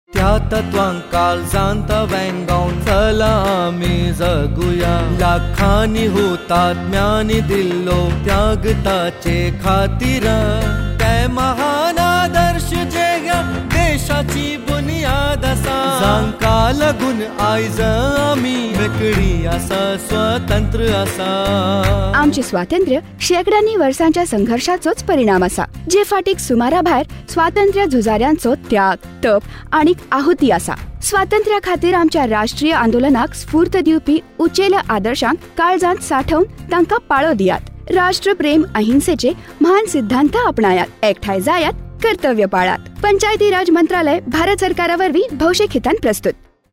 103 Fundamental Duty 2nd Fundamental Duty Follow ideals of the freedom struggle Radio Jingle Konkani